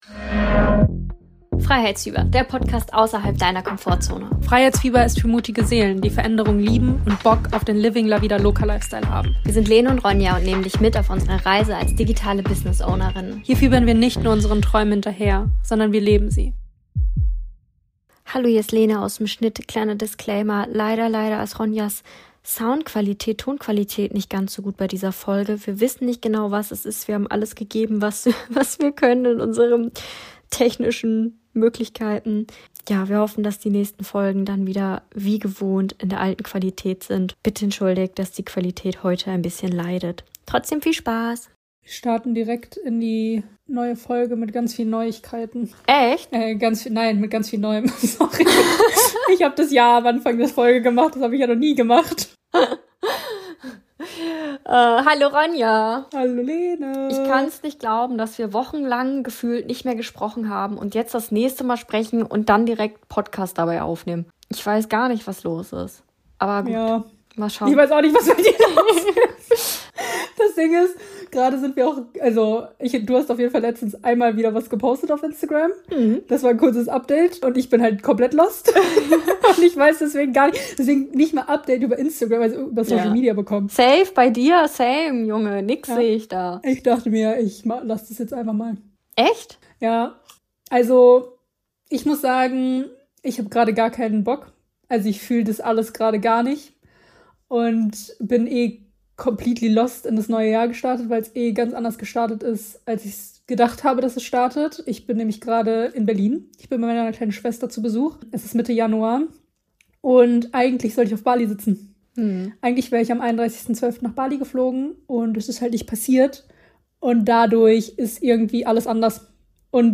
Mach dir einen Tee und hör uns beim Quatschen zu Mehr